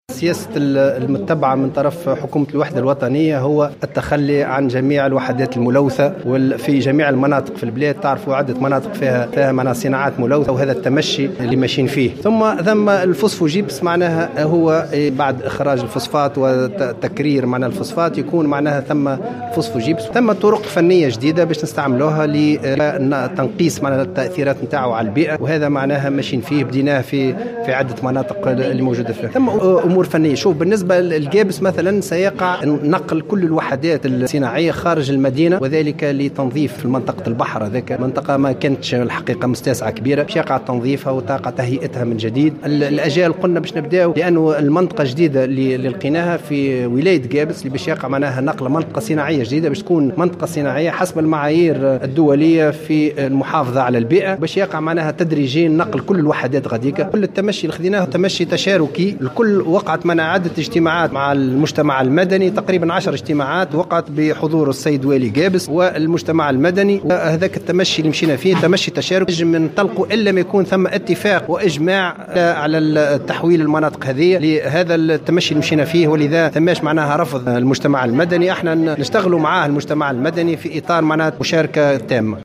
قال وزير الطاقة خالد قدور في تصريح لمراسل الجوهرة "اف ام" اليوم 5 مارس 2018 إن السياسة المتبعة من طرف حكومة الوحدة الوطنية هي التخلي عن جميع الوحدات الملوثة في كل مناطق البلادوابعاد المصانع عن مواطن العمران.